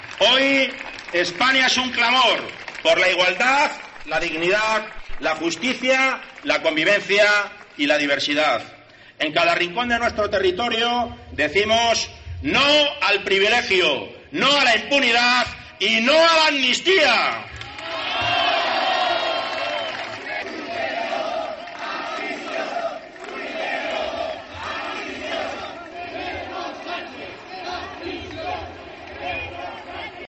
Lectura manifiesto contra la amnistía